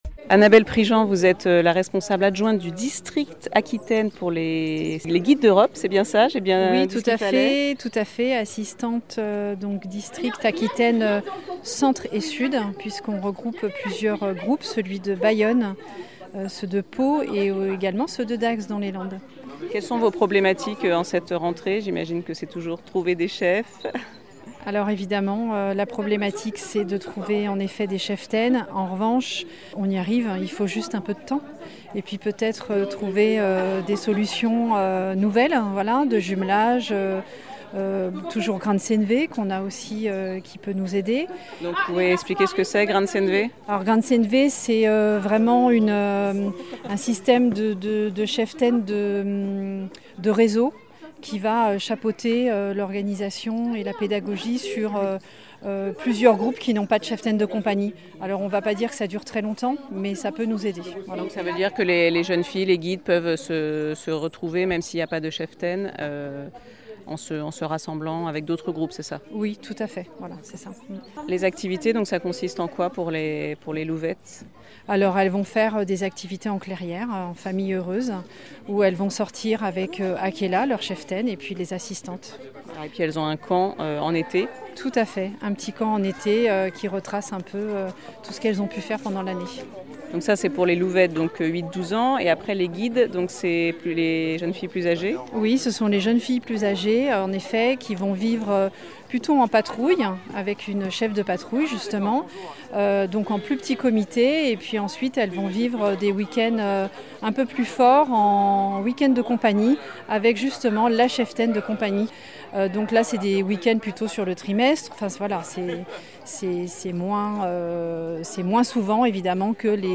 A l'occasion de la rentrée des scouts d'Europe de Bayonne, des responsables et cheftaines témoignent sur leur engagement.